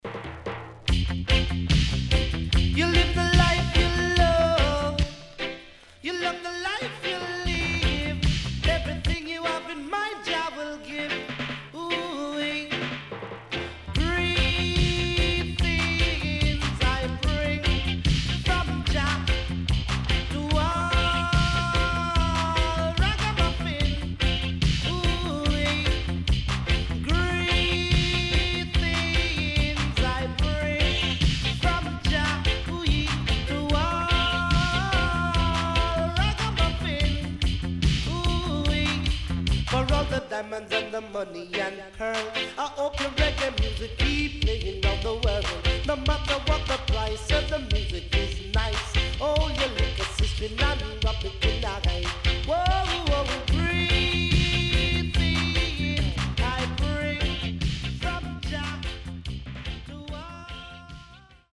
HOME > LP [DANCEHALL]
SIDE A:うすいこまかい傷ありますがノイズあまり目立ちません。